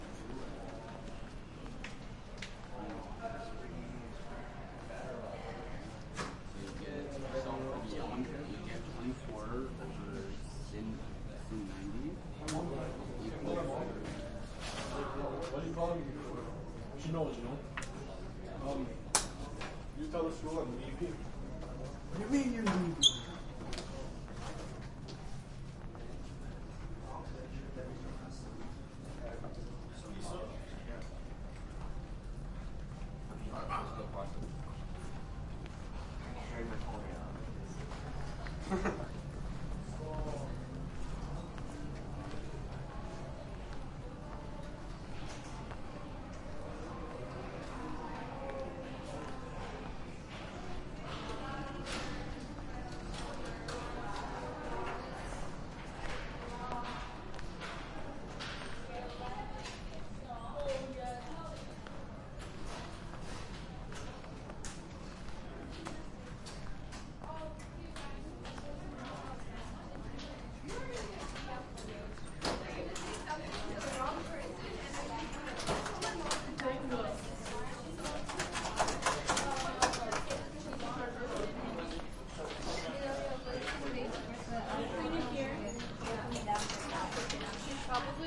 高中" 人群在高中的走廊上平静的光旅行的储物柜听到步行运动的咔嚓声+漂亮的储物柜响声结束
描述：人群int高中走廊平静光旅行储物柜听到步行运动点击+漂亮的储物柜拨浪鼓结束
Tag: 沉稳 INT 人群 学校 走廊